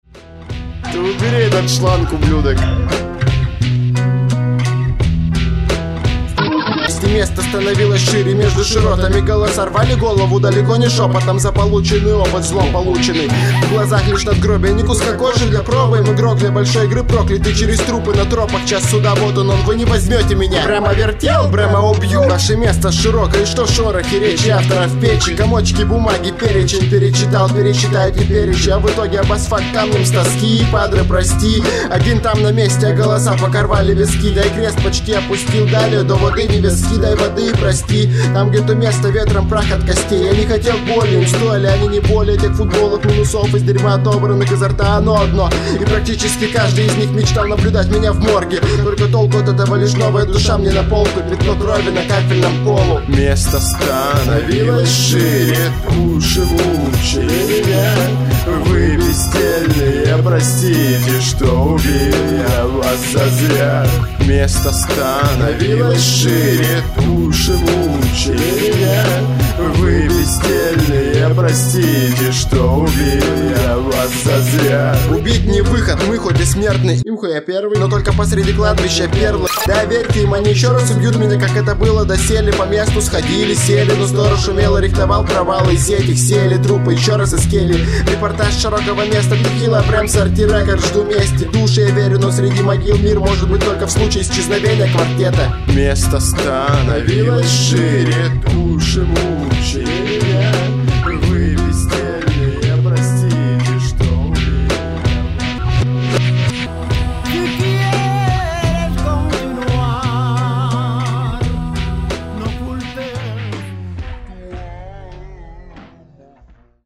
2006 Рэп